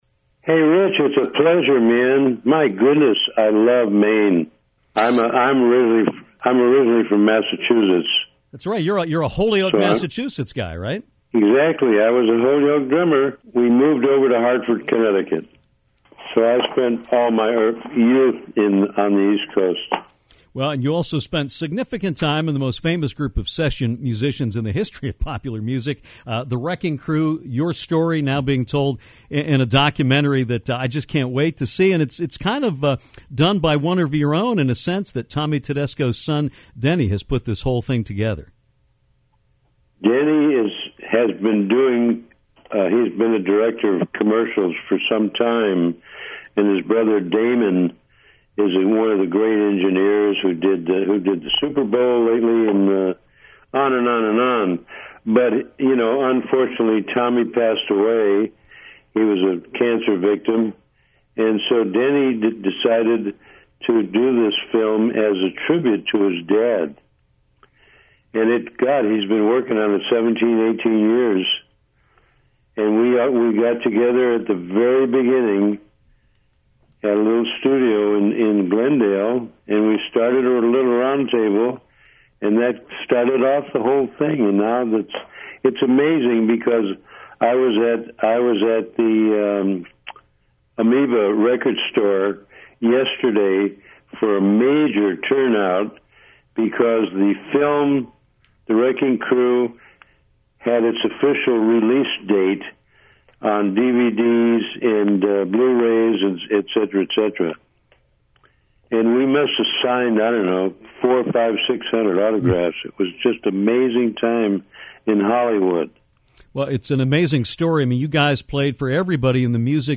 Rock ‘n Roll Hall of Famer Hal Blaine joined Downtown to talk about the new documentary on his session band The Wrecking Crew.